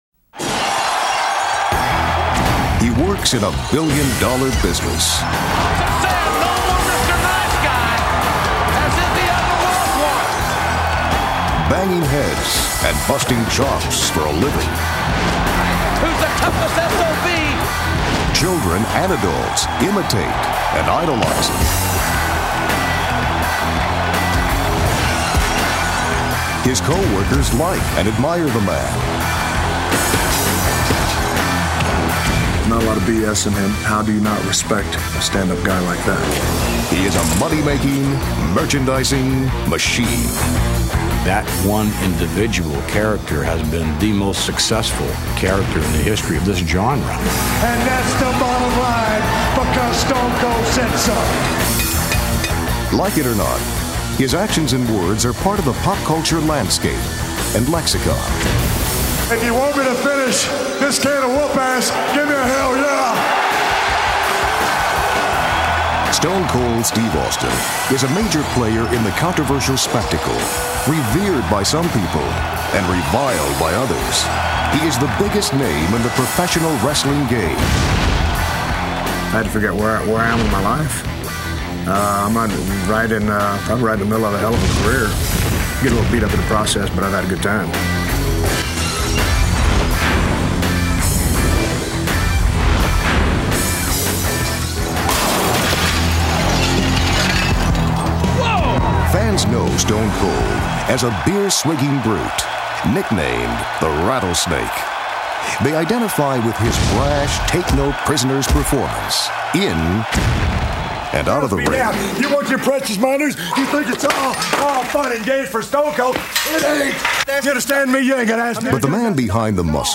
Male Voice Overs